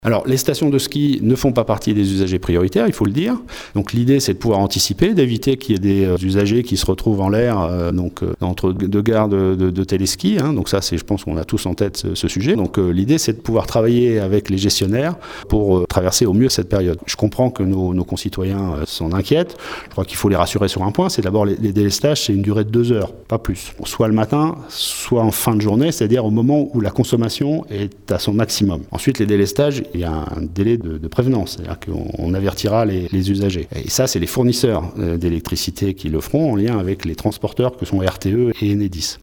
Mais les stations de ski en revanche n’auront pas de passe-droit, comme nous le confirme Yves le Breton, préfet de la Haute-Savoie